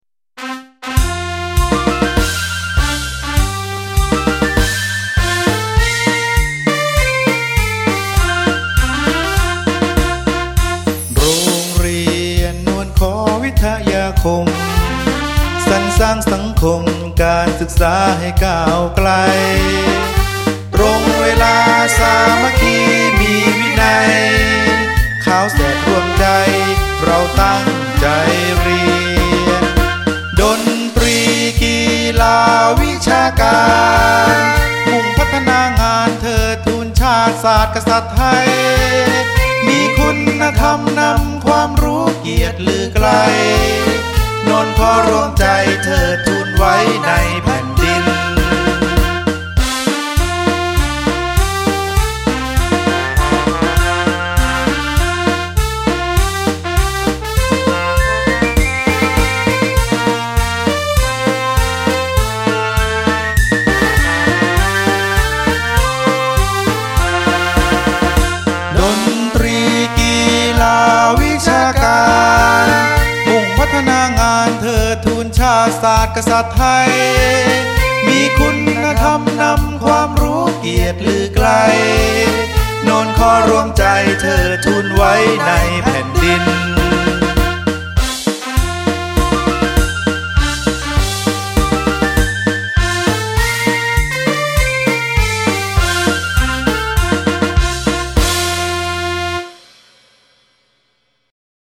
จังหวะ  มาร์ช